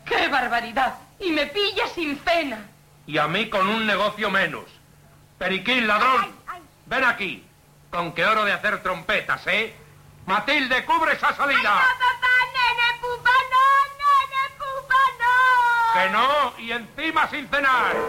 Fragment del serial, trifulga a l'hora de sopar entre Periquín i Perico
Ficció